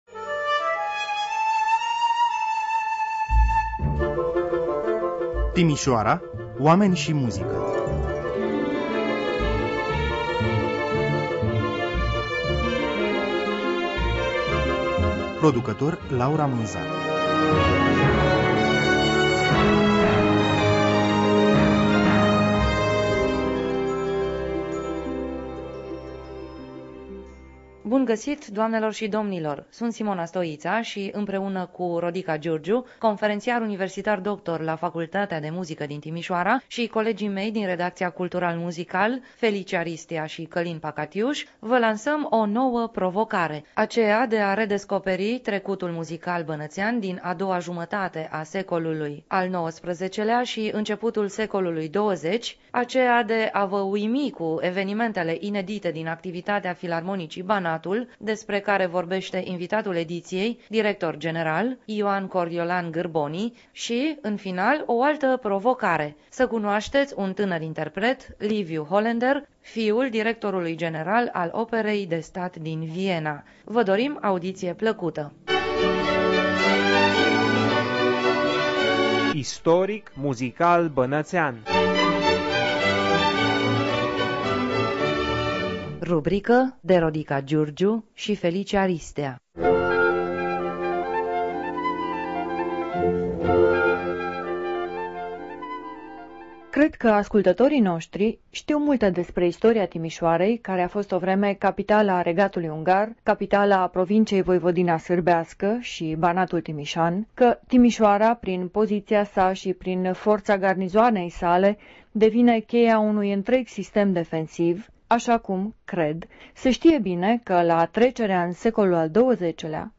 Timisoara �n a doua jumatate a secolului al XIX-lea si �nceputul secolului al XX-lea Interviul editiei